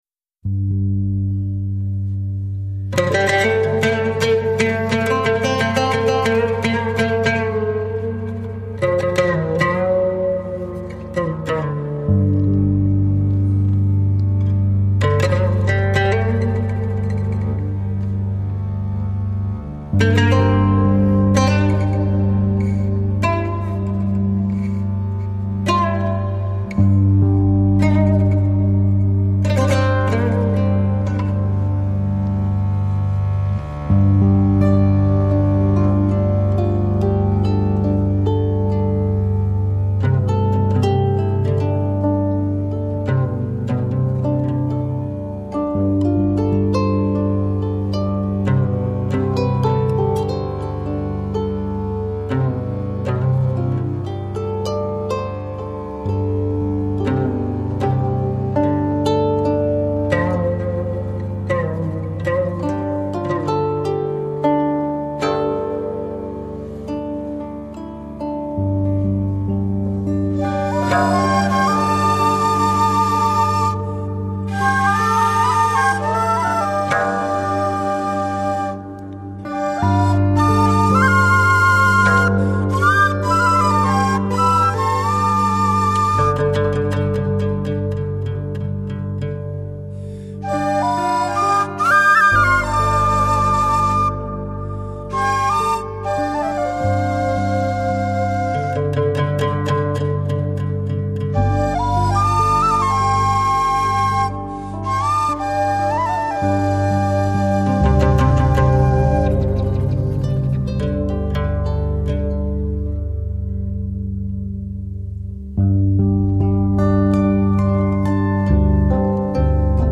Oud
zither, dizi, quenacho, whistle, ba-wu, fujara
soprano sax
triple harp
double bass